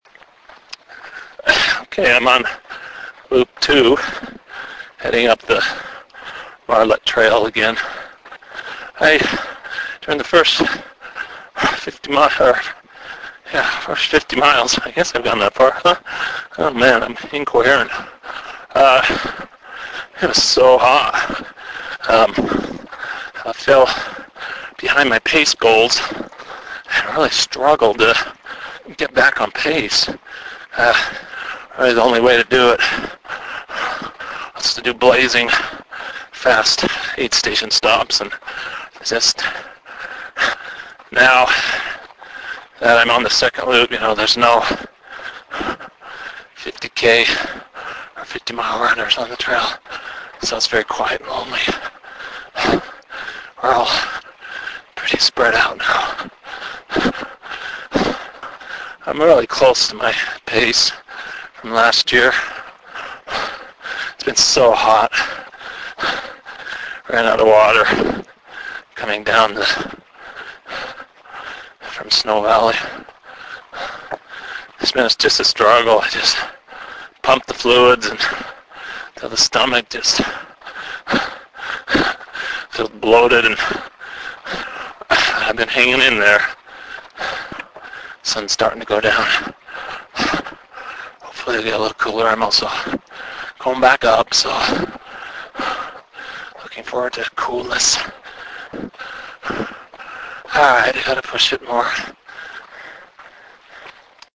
Listen to audio clip on the Marlette Trail.